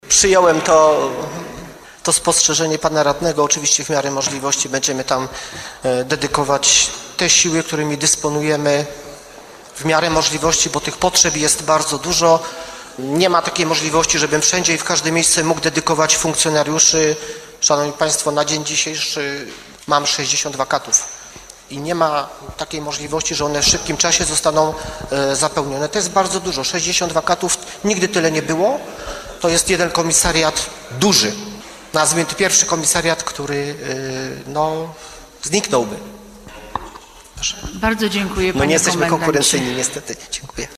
O 60 wakatach w bielskim garnizonie mówił dziś w trakcie sesji insp. Krzysztof Herzyk – Komendant Miejski Policji w Bielsku-Białej.